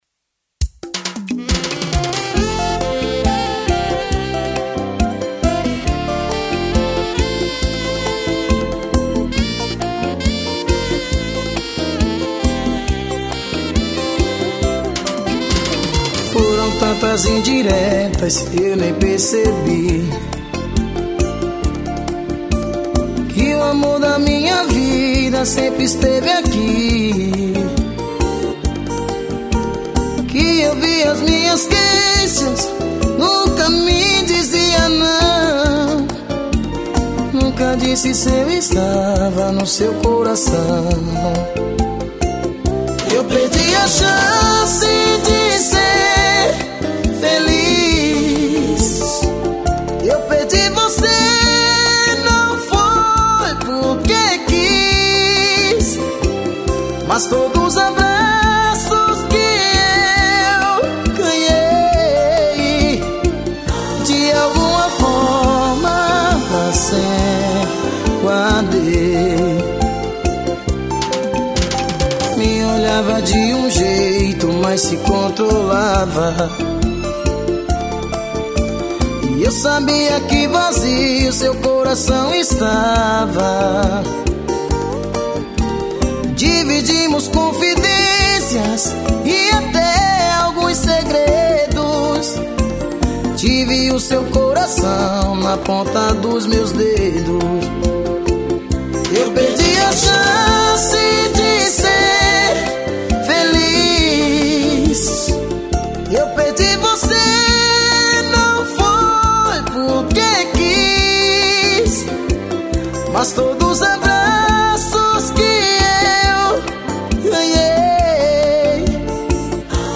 ao vivo..